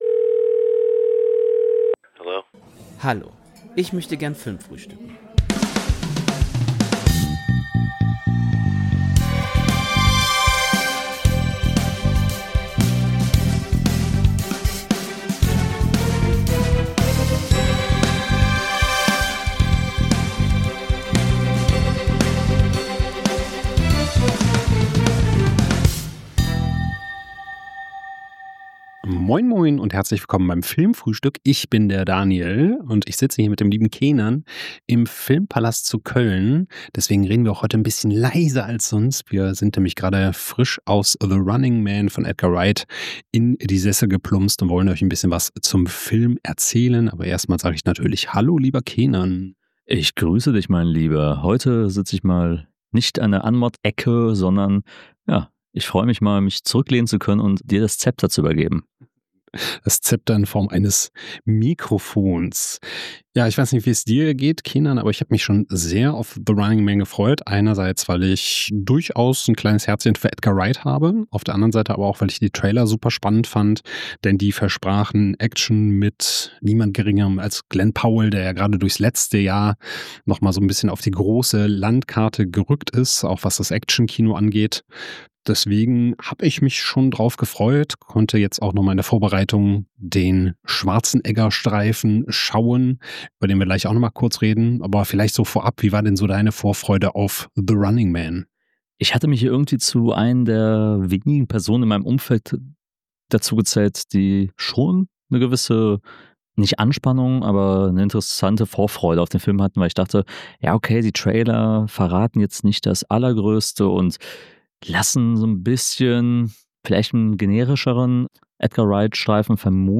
Aus dem gemütlichen Filmpalast in Köln berichten die beiden von ihren Eindrücken direkt nach dem Kinobesuch.